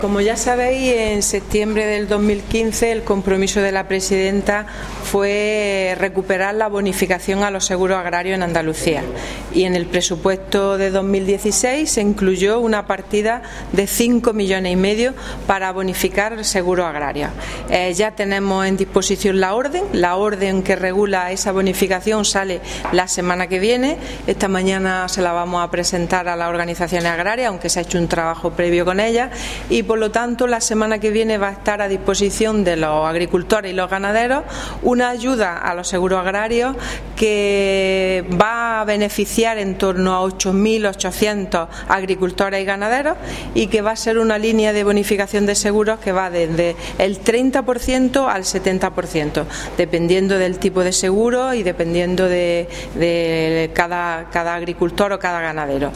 Declaraciones de la consejera sobre seguros agrarios